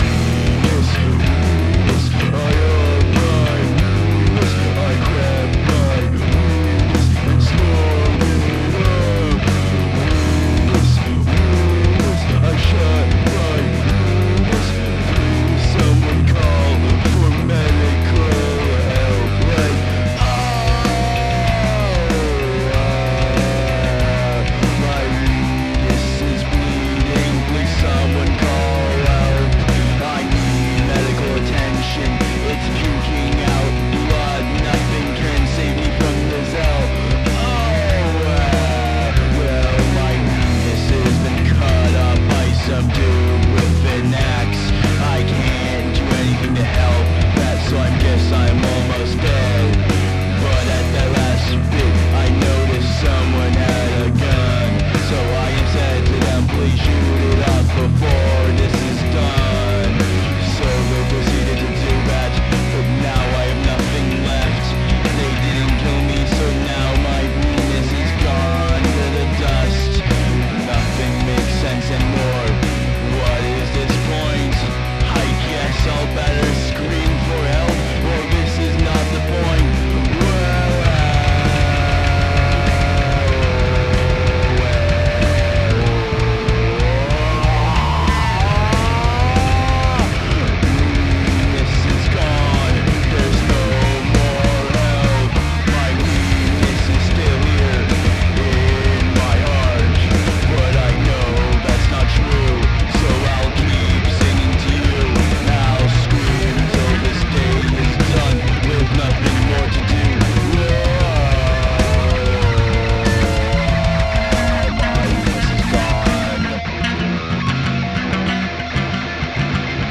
The May sessions were pretty much just one continuous improv with no planning or coherence.